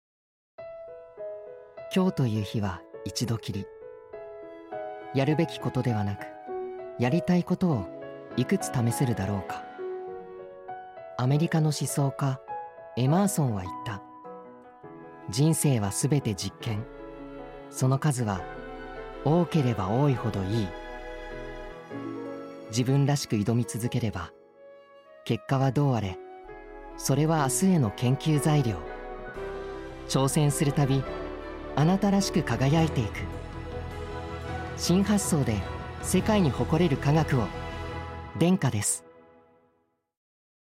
ラジオCM
denka_radiocm_thursday.mp3